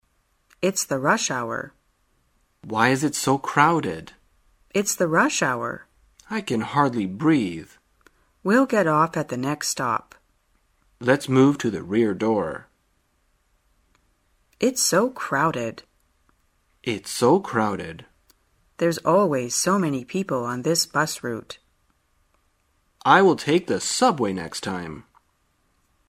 在线英语听力室生活口语天天说 第83期:怎样表达拥挤的听力文件下载,《生活口语天天说》栏目将日常生活中最常用到的口语句型进行收集和重点讲解。真人发音配字幕帮助英语爱好者们练习听力并进行口语跟读。